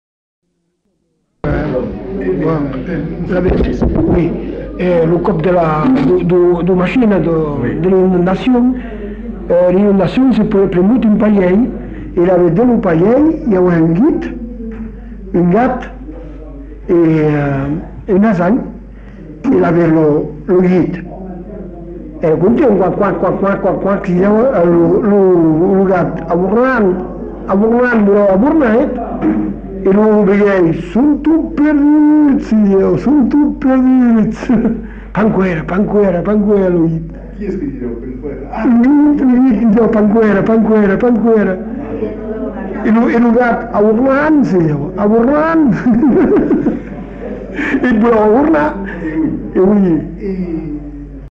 Mimologisme
Aire culturelle : Bazadais
Lieu : Bazas
Genre : forme brève
Type de voix : voix d'homme
Production du son : récité